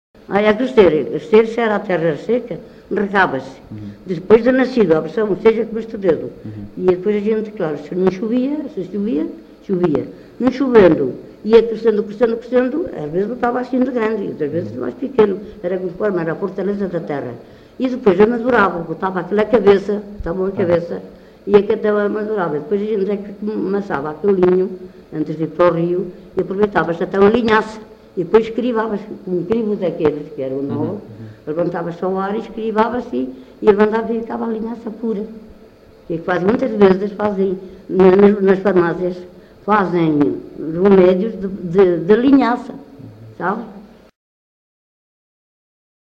LocalidadeVilar de Perdizes (Montalegre, Vila Real)